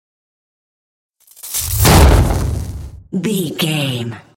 Dramatic whoosh to hit trailer
Sound Effects
Fast paced
In-crescendo
Atonal
dark
intense
tension
woosh to hit